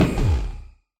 Sound / Minecraft / mob / enderdragon / hit2.ogg
should be correct audio levels.
hit2.ogg